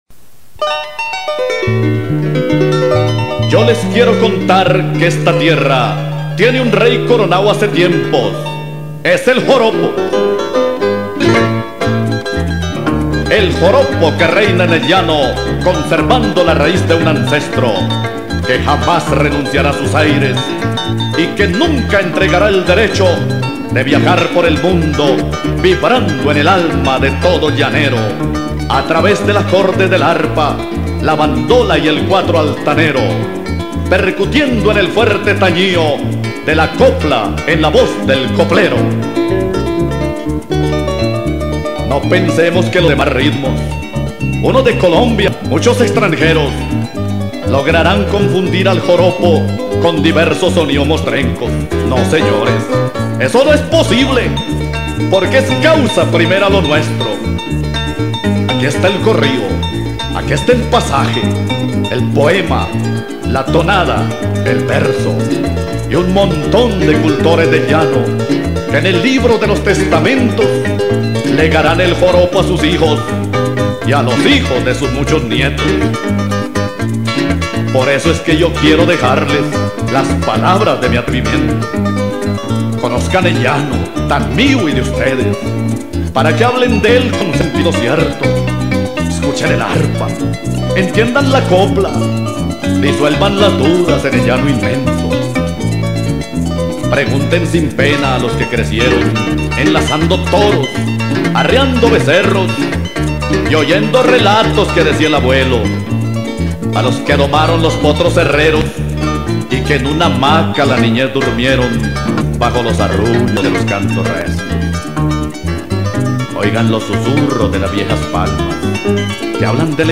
Ritmo: Poema.